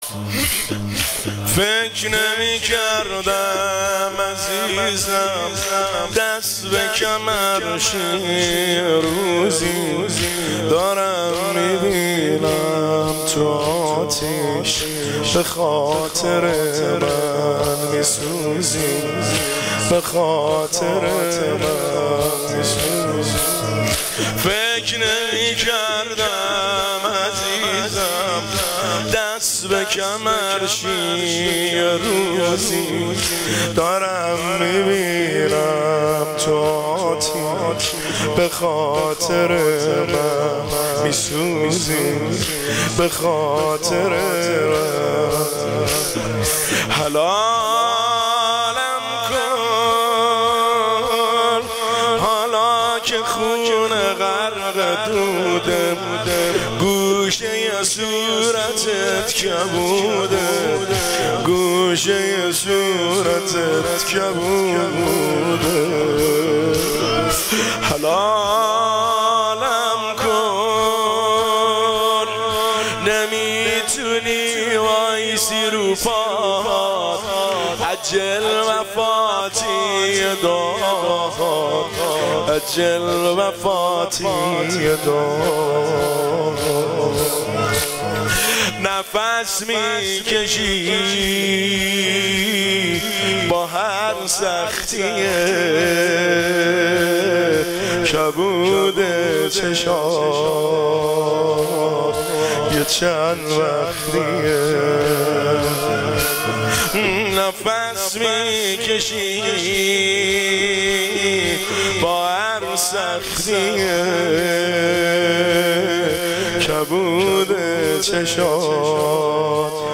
زمینه  شب دوم فاطمیه دوم 1404
دانلود با کیفیت LIVE